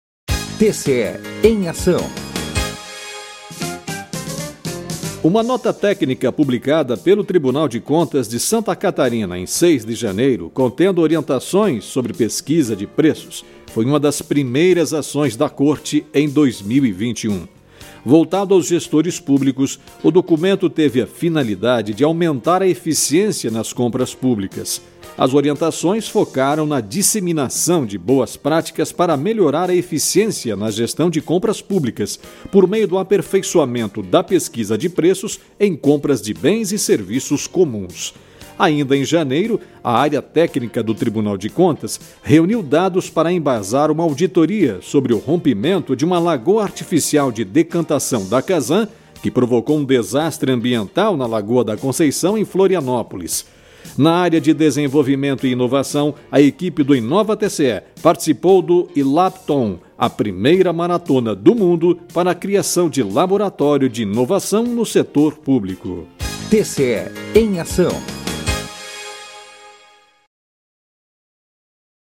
VINHETA – TCE EM AÇÃO